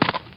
PixelPerfectionCE/assets/minecraft/sounds/mob/horse/soft2.ogg at mc116